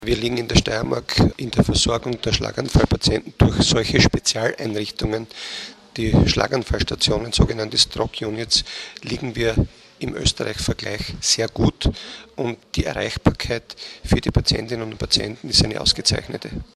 Pressekonferenz zum Thema integrierte Versorgung von Schlaganfallpatienten